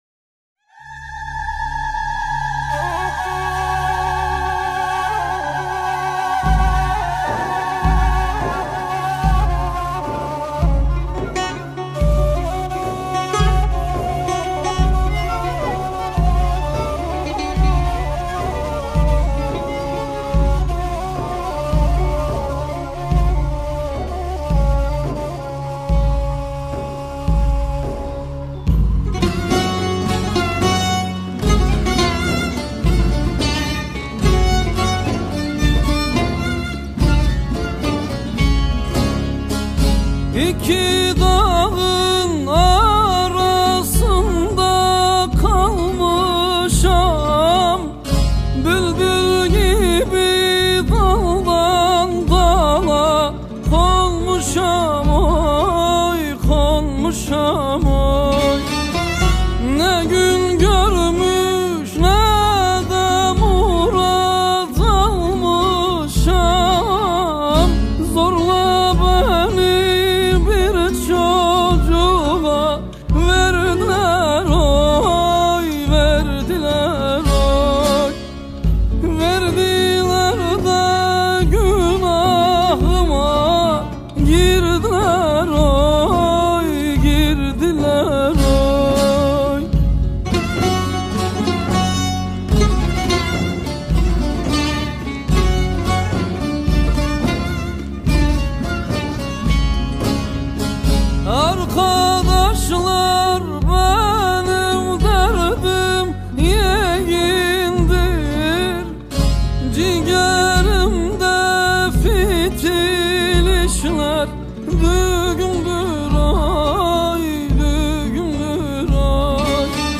موسیقی احساسی و عربسک ترکیه‌ای